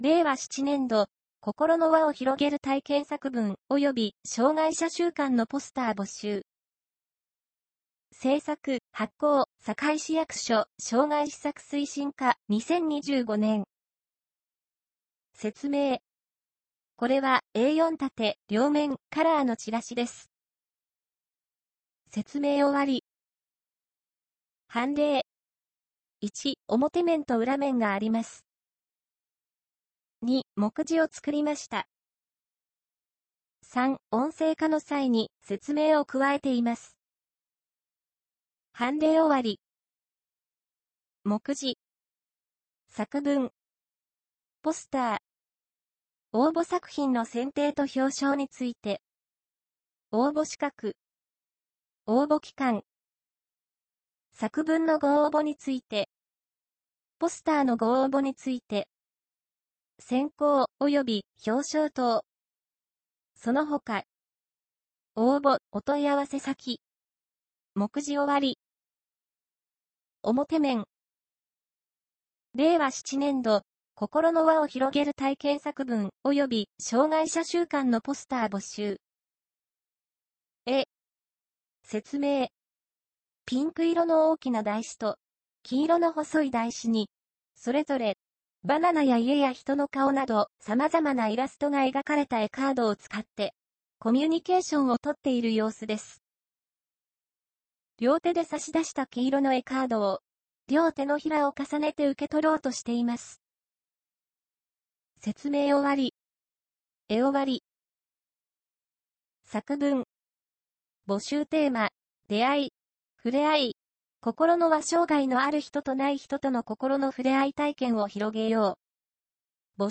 音声版チラシ